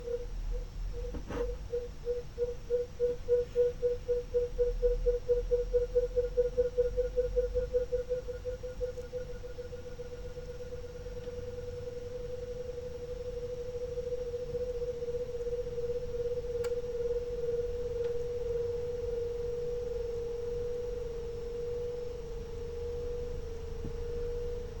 Bruit au démarrage climatiseur Toshiba Yukai
J'ai remarqué un bruit assez particulier au moment du démarrage.
- Le ventilateur du module extérieur démarre (je l'entends en passant la tête par la fenêtre) et au bout de 30 secondes, le bruit bizarre apparaît.
On entend clairement une accélération d'un élément tournant, cela pourrait être le compresseur, mais les réponses aux questions ci-dessus permettront d'être plus affirmatif.
Donc vu vos réponses, c'est bien le compresseur qui fait ce bruit.
bruit-clim-thoshiba-yukai.mp3